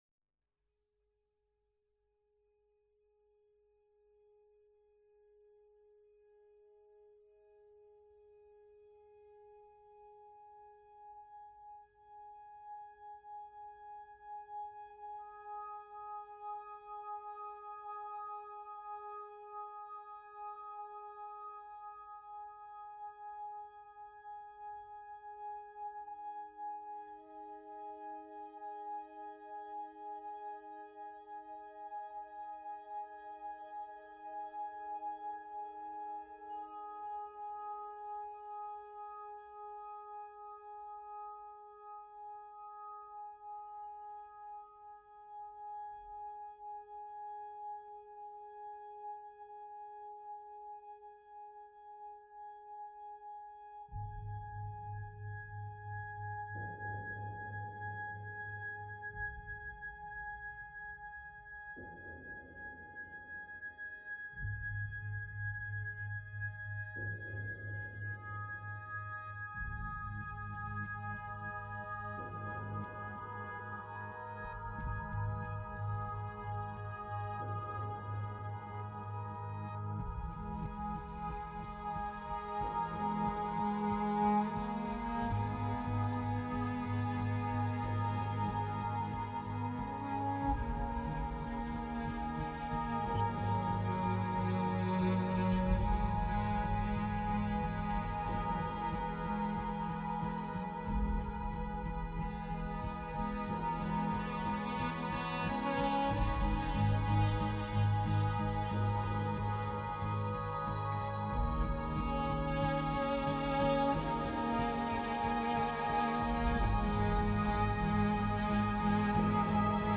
اگه طرفدار موسیقی ارام هستید از دست ندید.